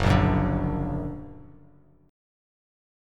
Listen to Gb+ strummed